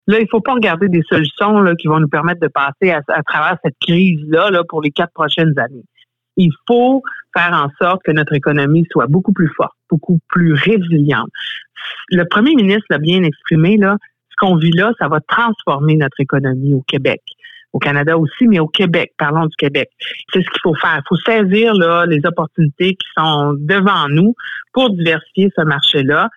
En entrevue à la radio CFMF cette semaine, elle indique avoir entamé un travail sur le terrain pour entendre les préoccupations à ce sujet :